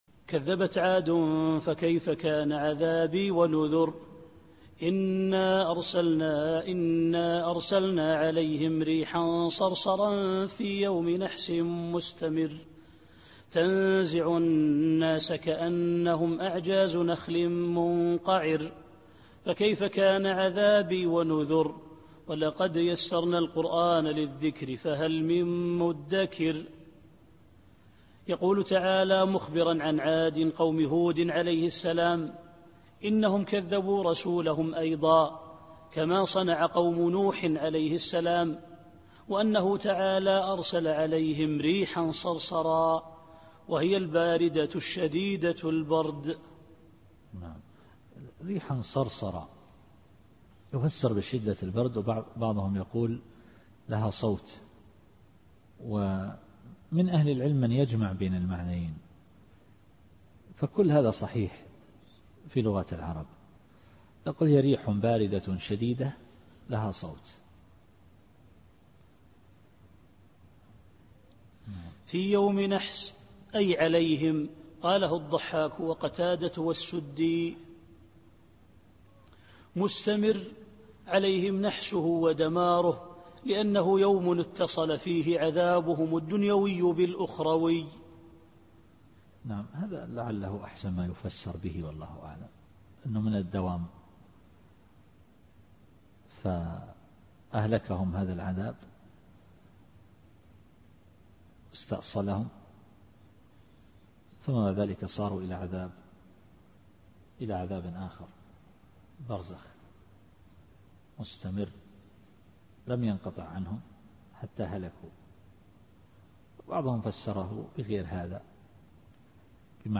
التفسير الصوتي [القمر / 19]